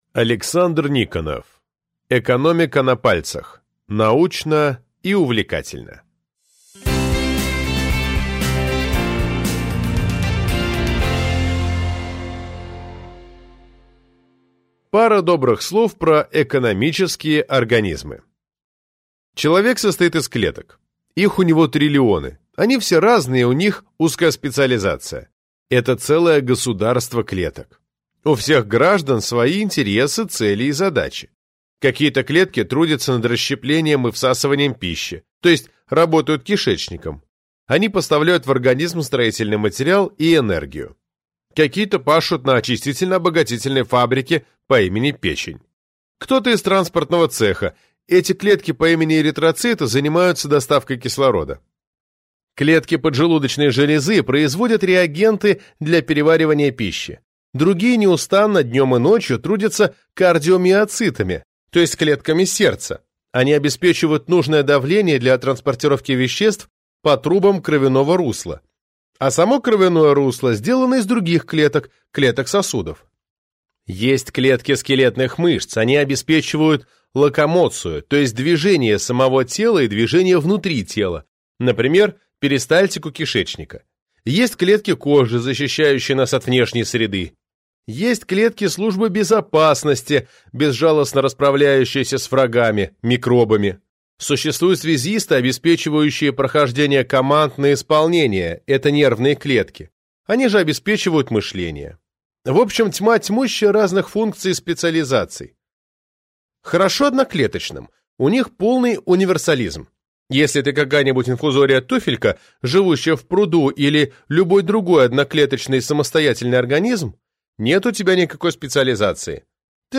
Аудиокнига Экономика на пальцах: научно и увлекательно | Библиотека аудиокниг